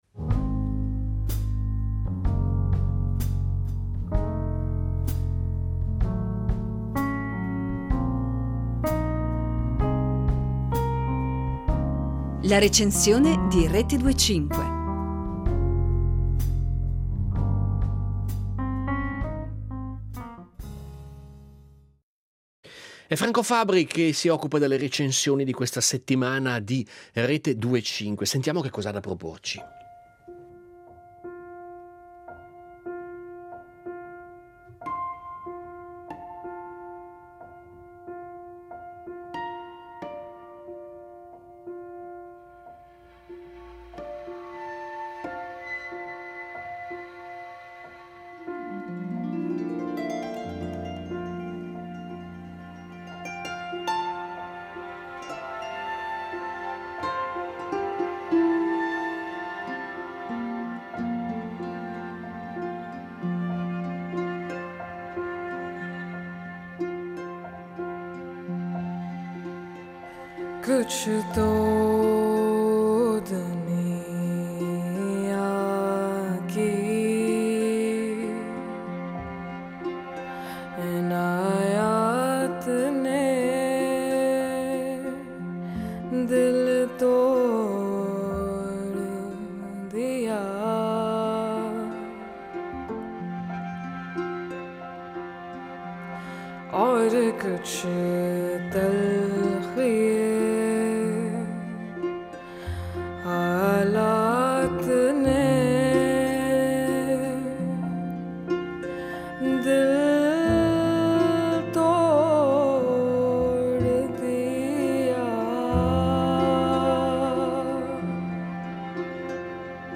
Grandi spazi intorno alla voce, bellissime melodie.